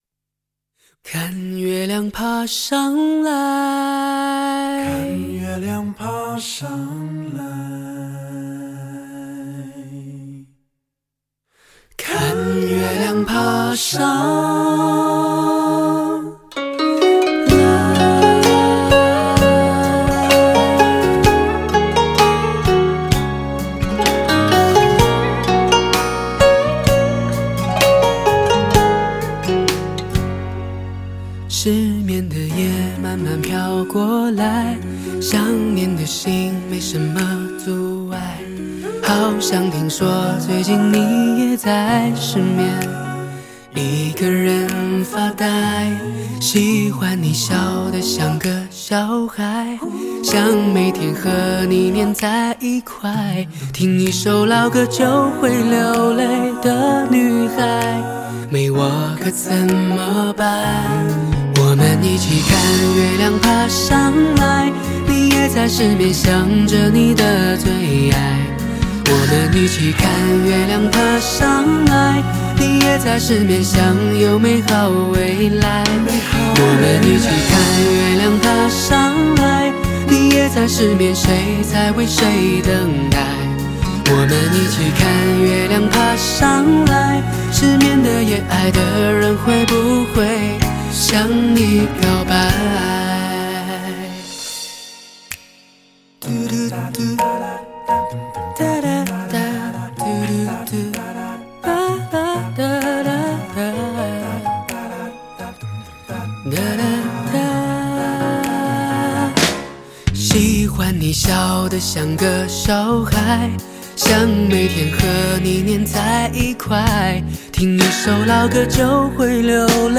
在线试听为压缩音质节选